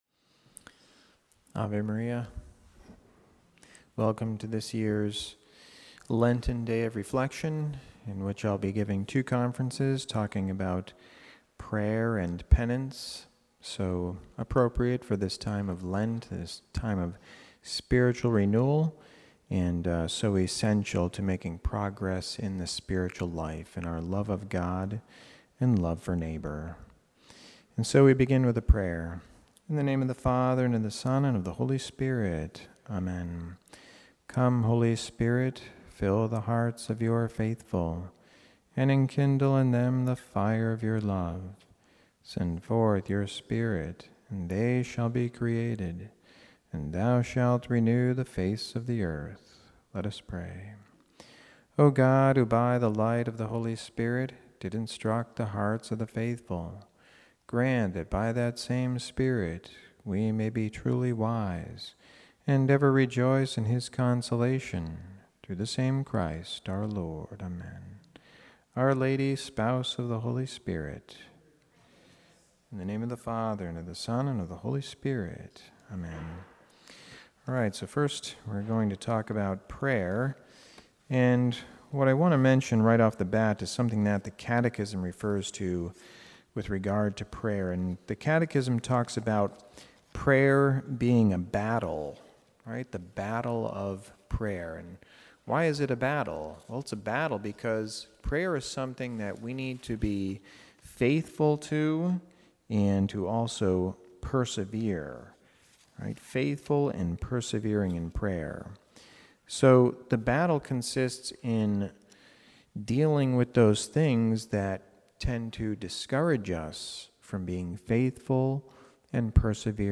Lenten Reflection